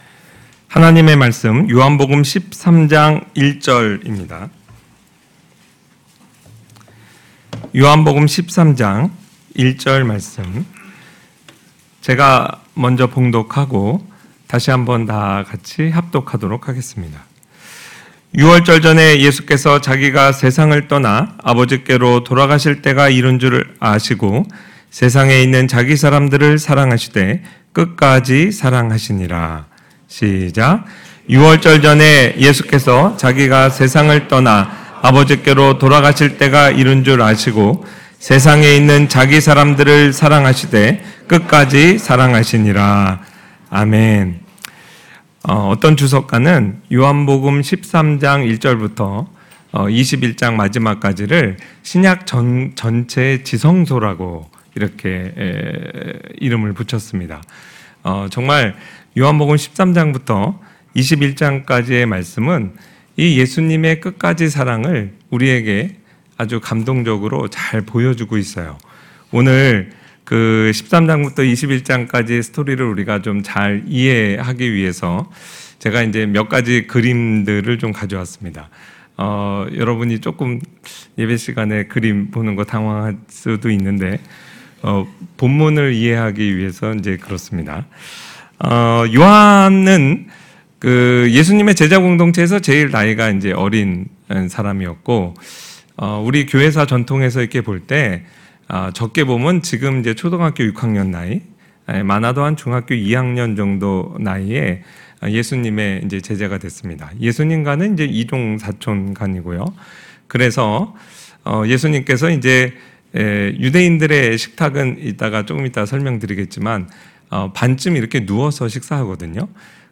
하늘영광교회 주일 설교